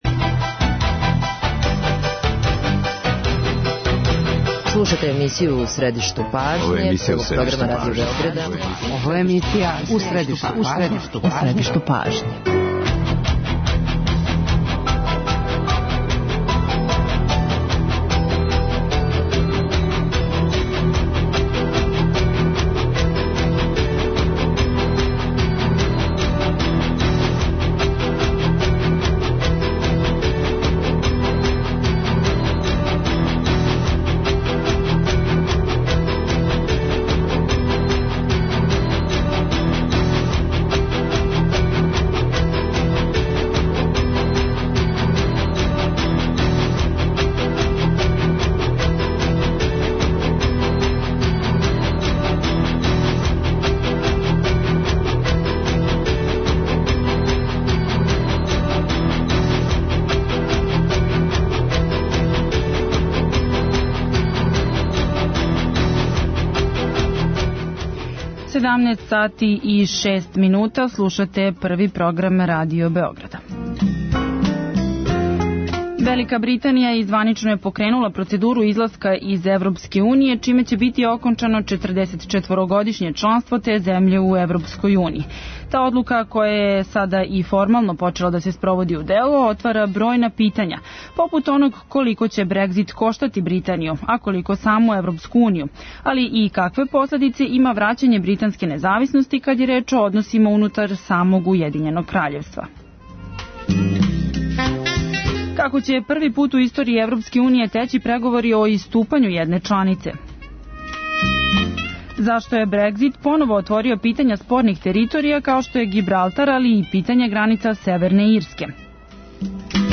Гости у студију су